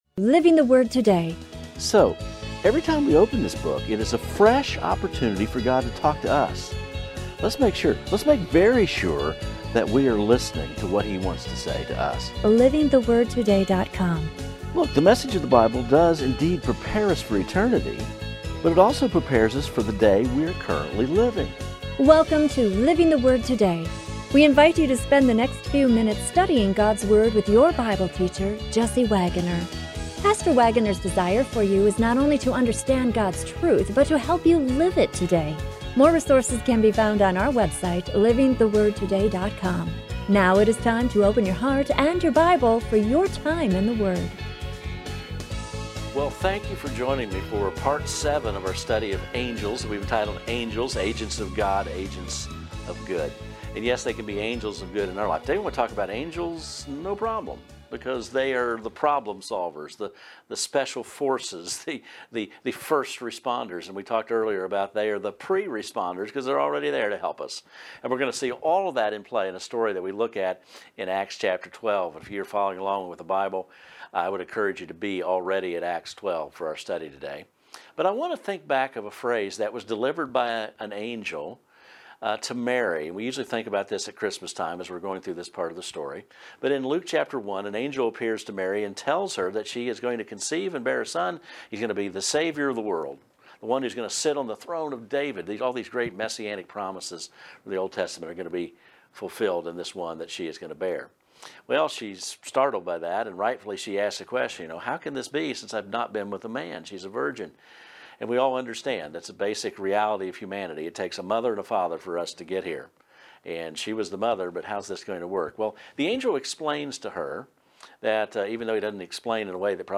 A study of angels in the book of Revelation reveals that victory is assured. The Praise Team sings “Child of Love.”